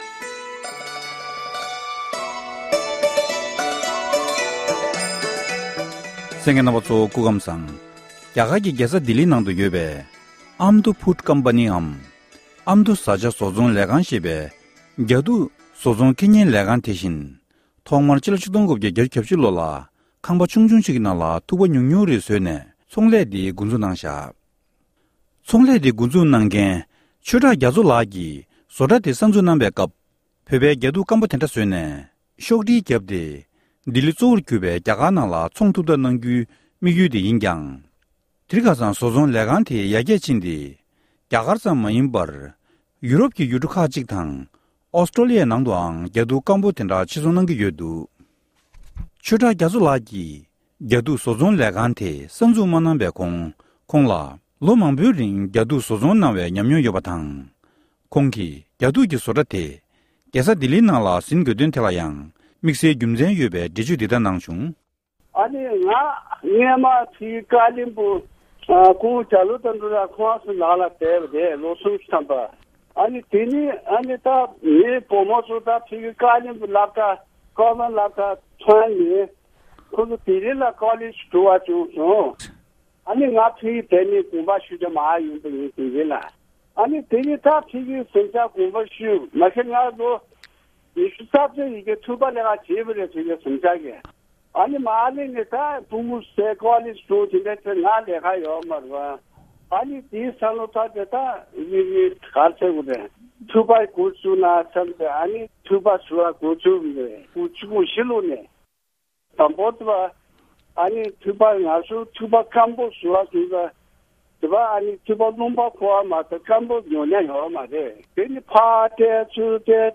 གནས་འདྲི་ཞུས་ཡོད༎